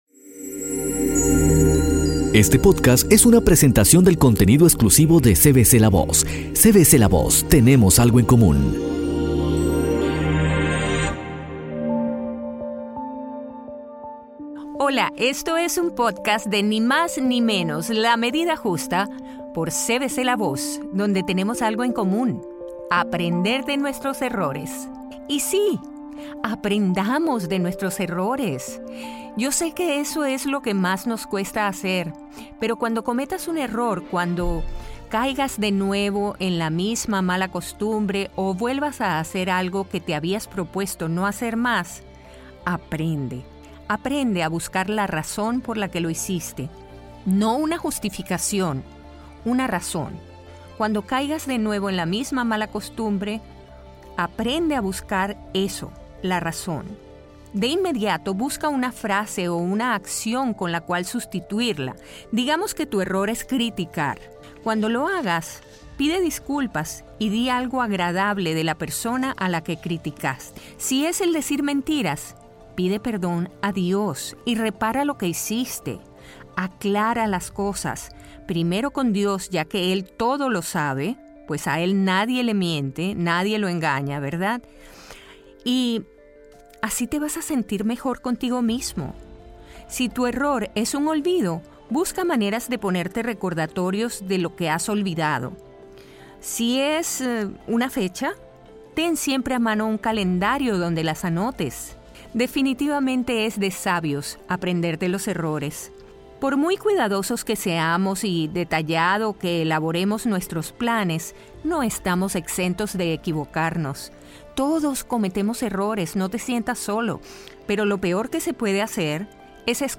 Este interesante tema, expuesto con dulzura, afabilidad y conocimiento